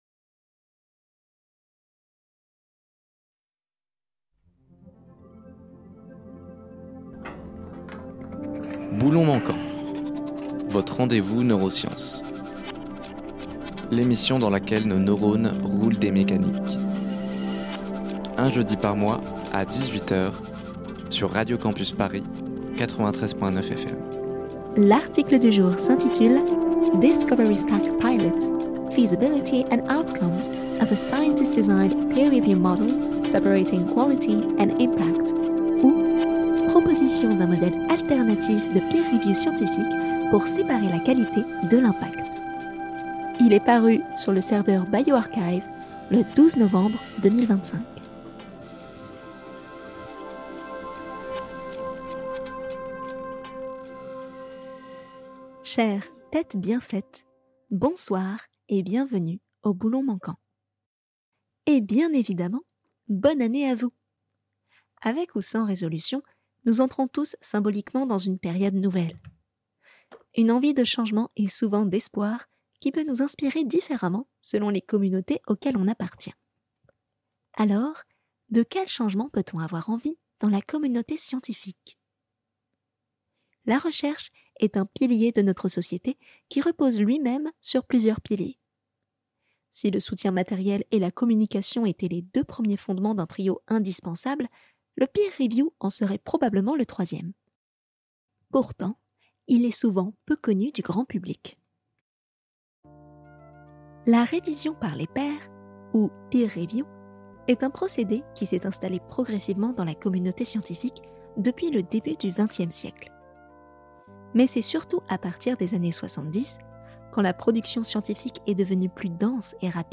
Partager Type Entretien Sciences jeudi 8 janvier 2026 Lire Pause Télécharger Si la communauté scientifique pouvait formuler de bonnes résolutions pour 2026, à quoi ressembleraient-elles ?